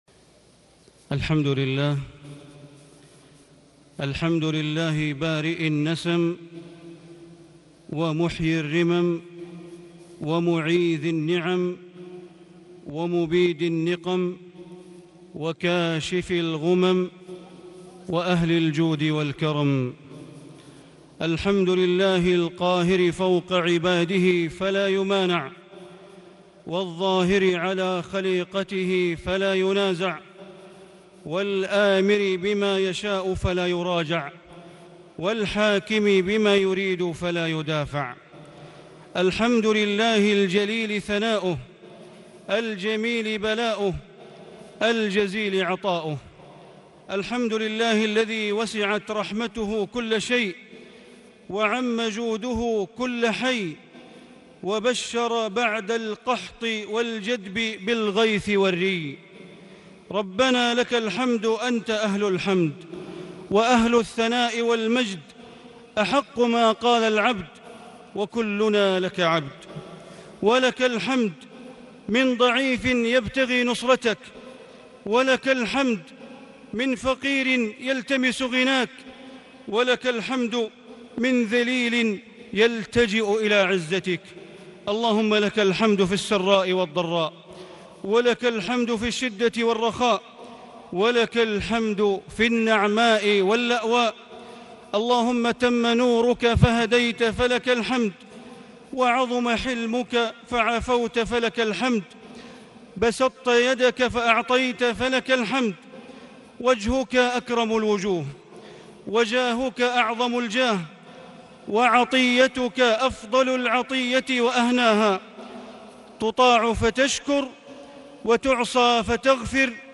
خطبة الاستسقاء 10 صفر 1438هـ > خطب الشيخ بندر بليلة من الحرم المكي > المزيد - تلاوات بندر بليلة